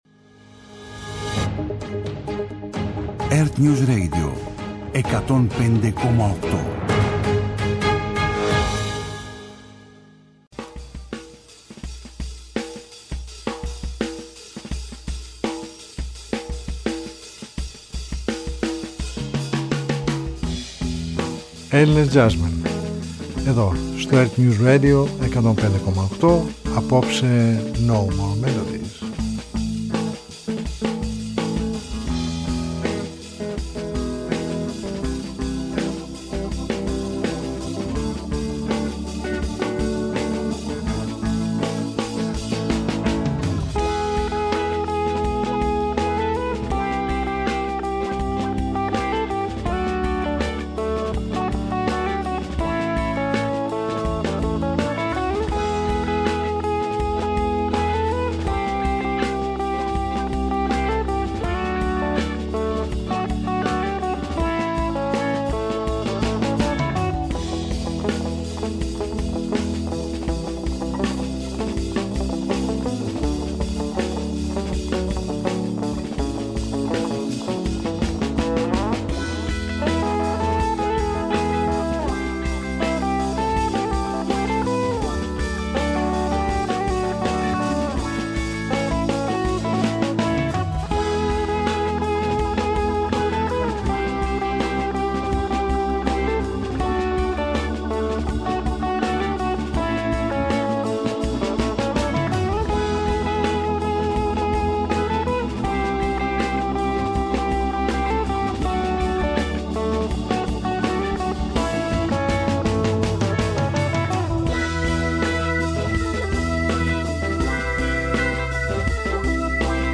παρουσιάζει επιλογές από την ελληνική jazz σκηνή.